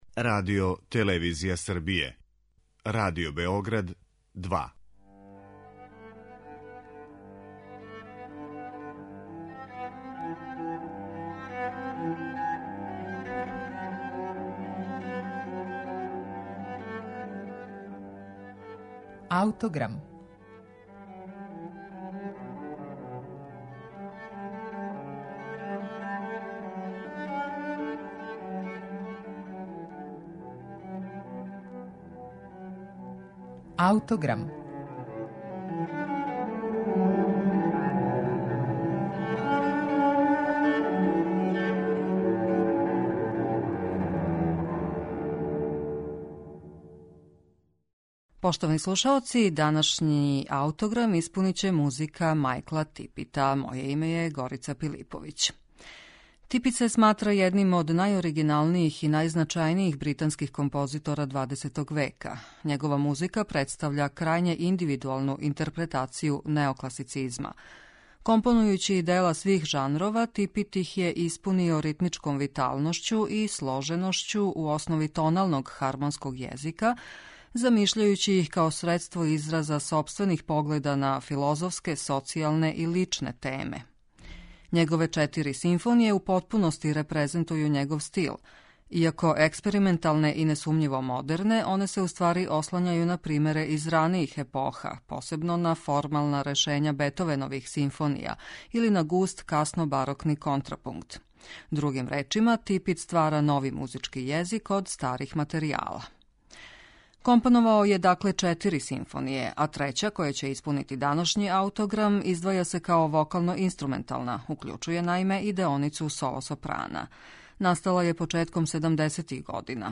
подразумева и људски глас